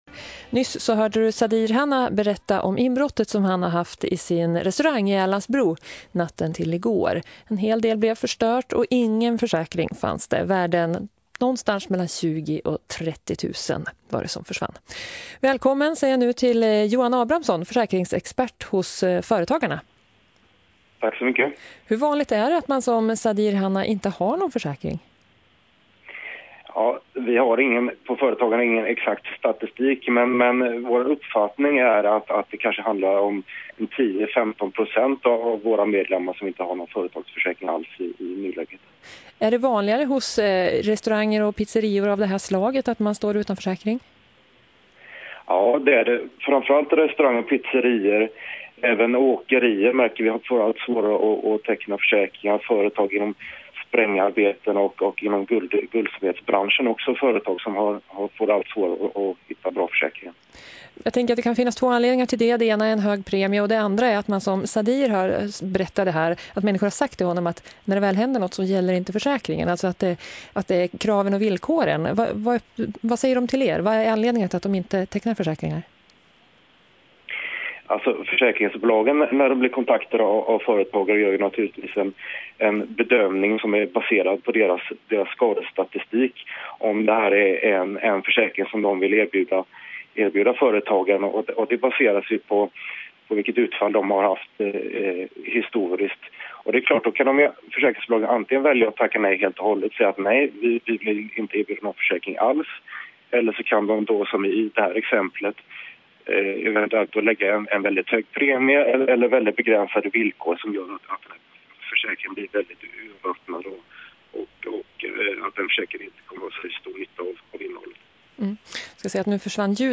intervjuades
i P4 Västernorrland, Sveriges Radio